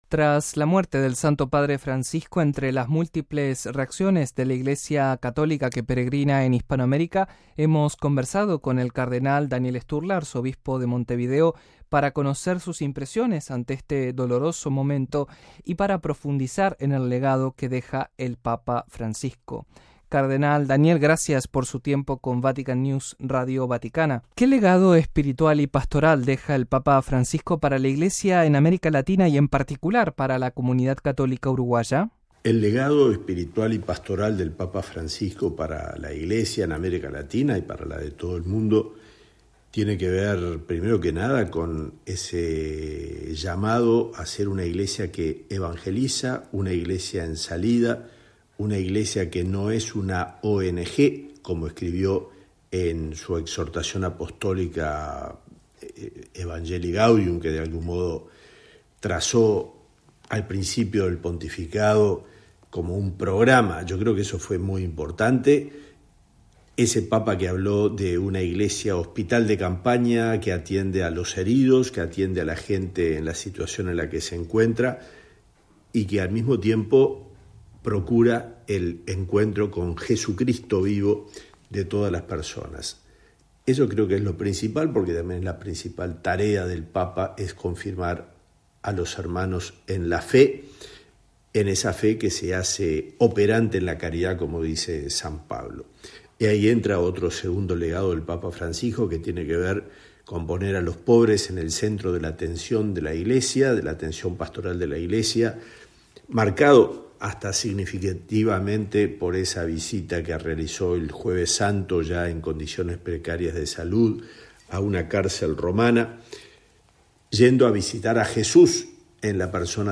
Listen to the Cardinal Daniel Storla data, head of the Montevideo Archbishop, Uruguay